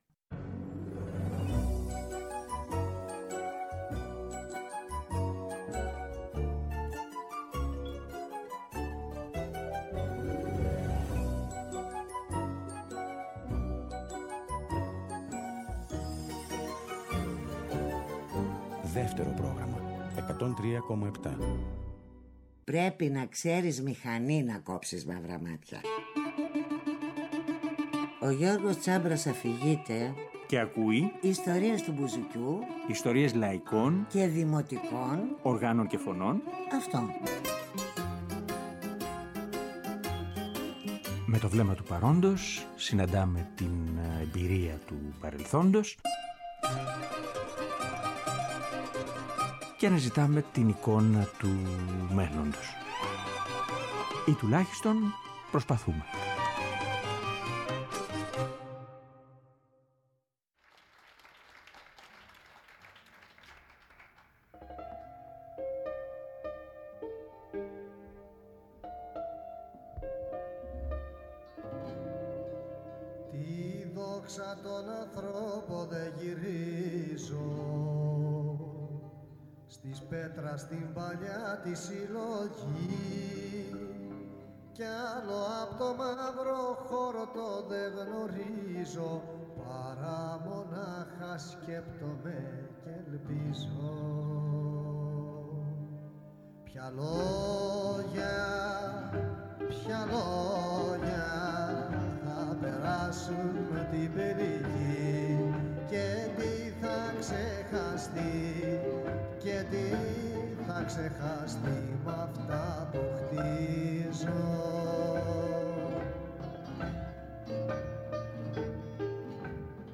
Μέσα από μια συνομιλία με τον ΚΩΣΤΑ ΓΕΩΡΓΟΥΣΟΠΟΥΛΟ ( Κ.Χ. ΜΥΡΗ ) στεκόμαστε στον τρόπο αλλά και στις συνθήκες μέσα από τις οποίες διαμορφώθηκε η «μυθολογία» των τραγουδιών του Γιάννη Μαρκόπουλου από τα μέσα της δεκαετίας του ’60 μέχρι και το 1980.